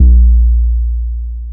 Hard 808 (JW3).wav